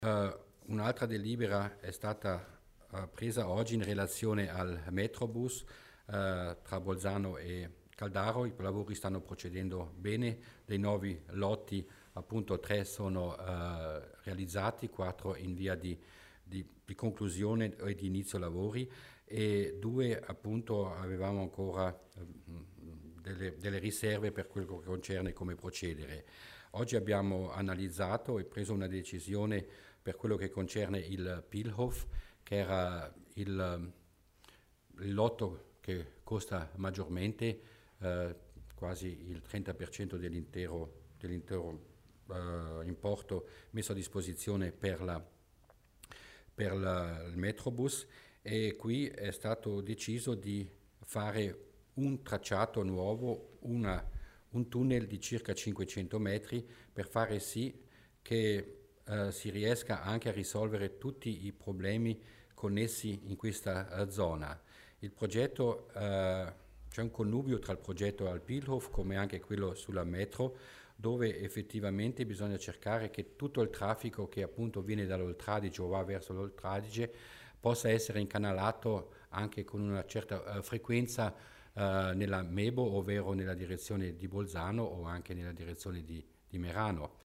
L'Assessore Mussner spiega le novità per il progetto Metrobus